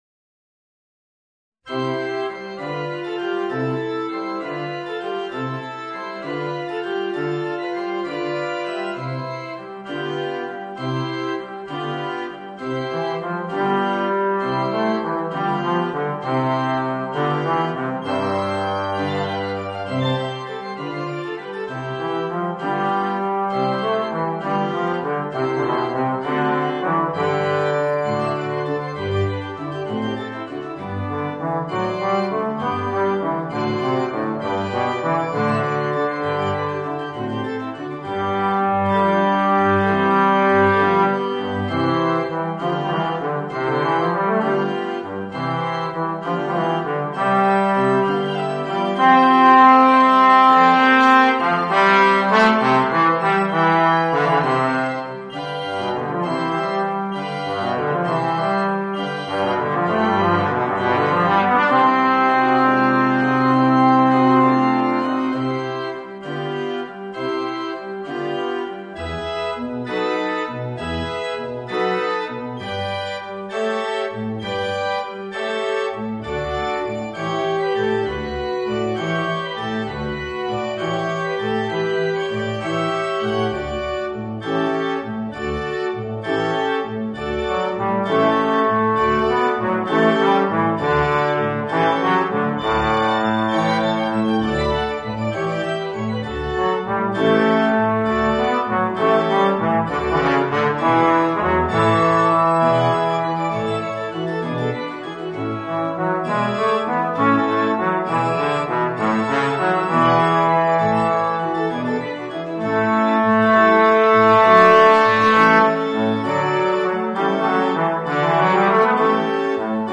Voicing: Bass Trombone and Organ